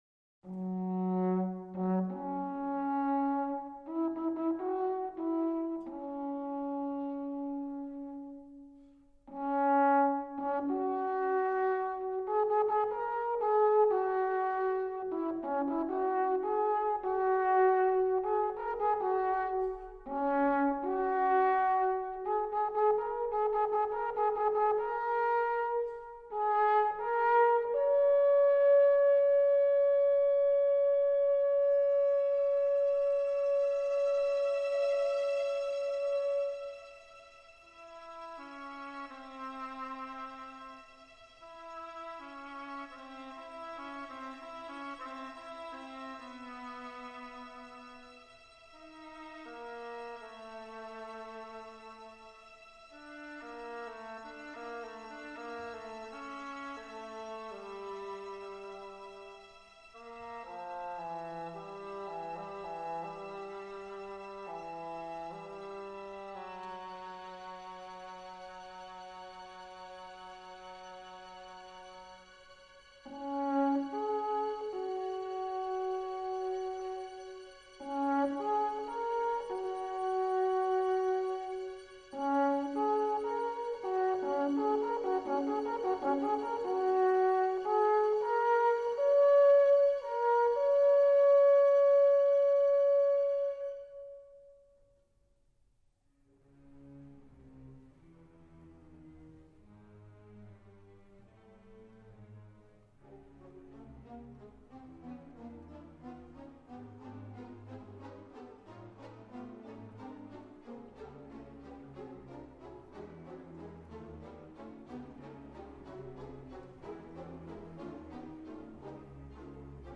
für Alphorn in Gb und Klavier Schwierigkeit